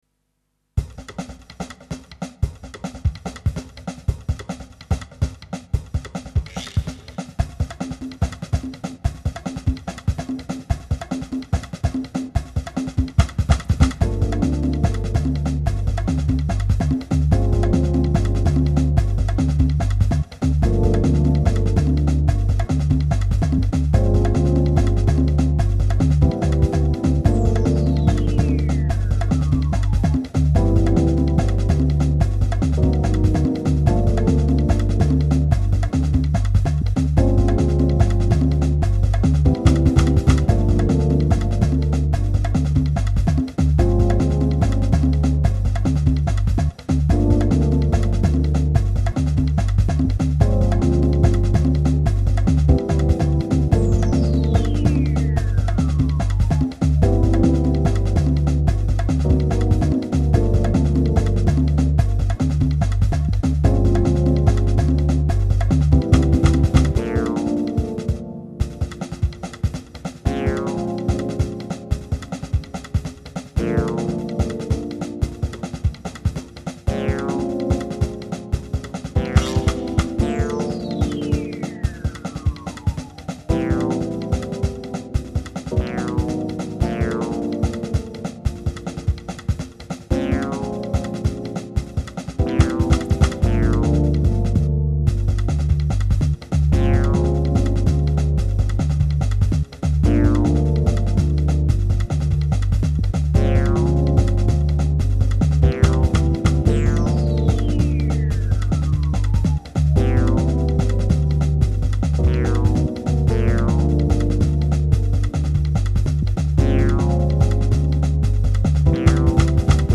dance/electronic
Breaks & beats
World beats
NuJazz